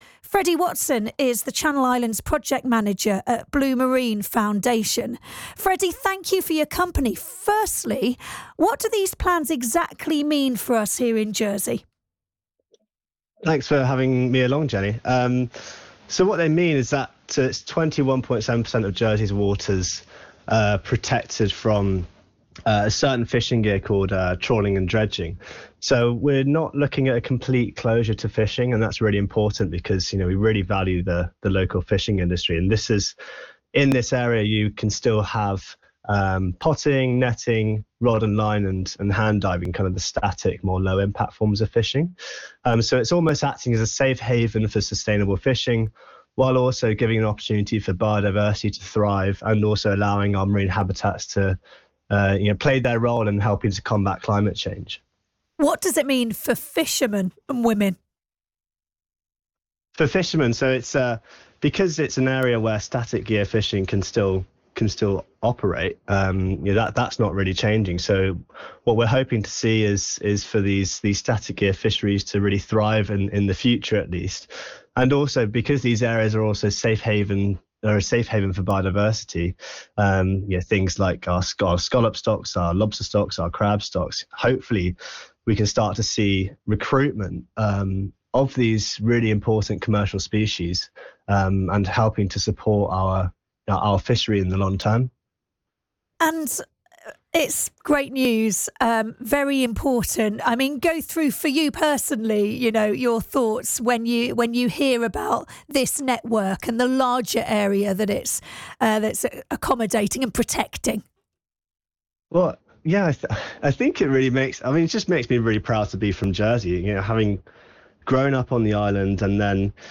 Interview: Jersey's new marine protected areas
You can listen to the BBC Radio Jersey interview here.